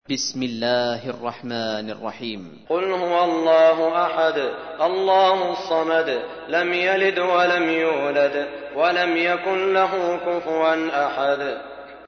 تحميل : 112. سورة الإخلاص / القارئ سعود الشريم / القرآن الكريم / موقع يا حسين